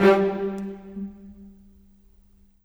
Rock-Pop 07 Cello _ Viola 02.wav